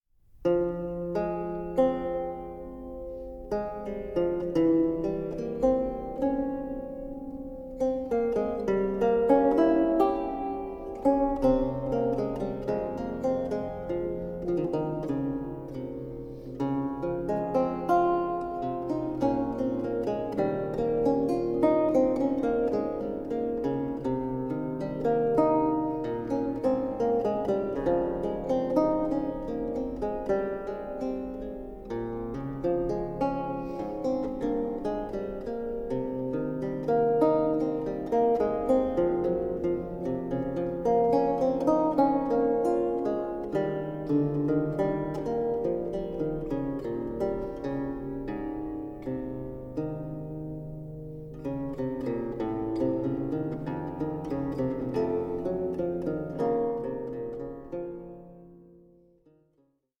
liuto attiorbato